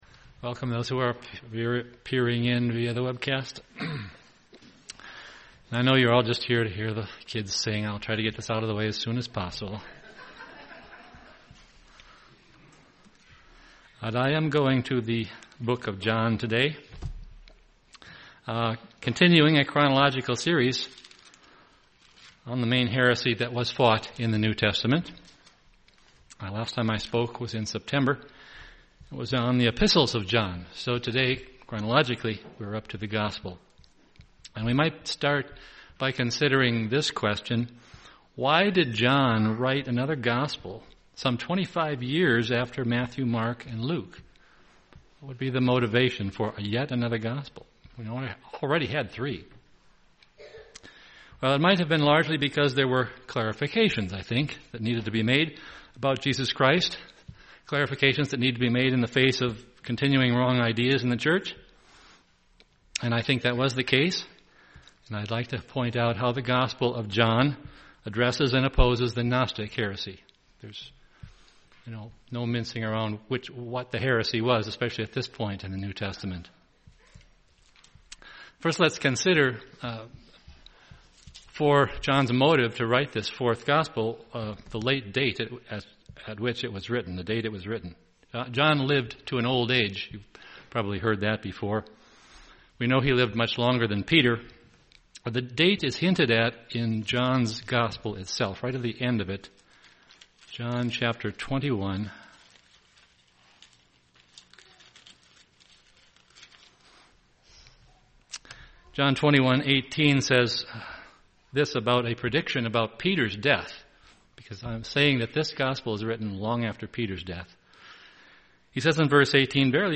This sermon compares the doctrines of John's gospel against the gnostic heresy that was being taught by Cerinthus and others in the Greek-speaking churches.
Given in Twin Cities, MN